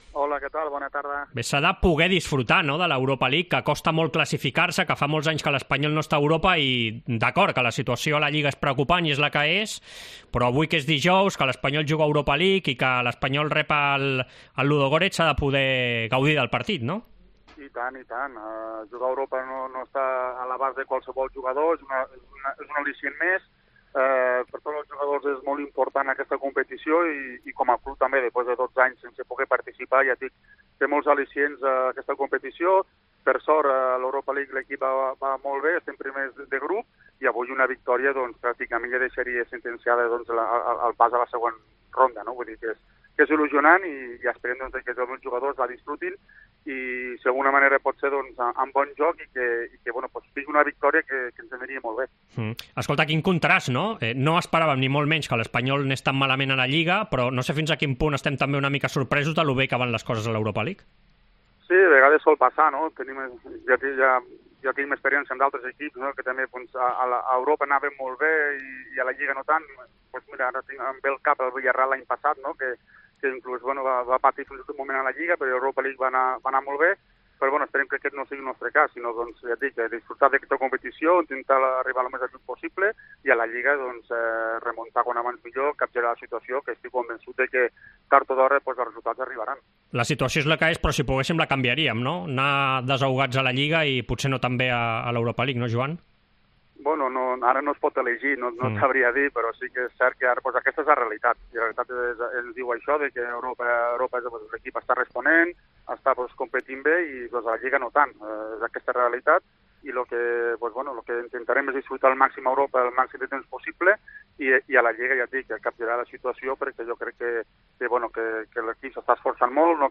AUDIO: Entrevista al portaveu del RCD Espanyol, Joan Capdevila, en la prèvia del partit contra el Ludogorets